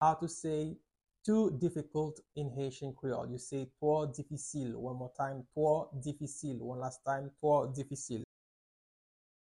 Listen to and watch “Twò difisil” audio pronunciation in Haitian Creole by a native Haitian  in the video below:
14.How-to-say-Too-Difficult-in-Haitian-Creole-–-Two-difisil-pronunciation.mp3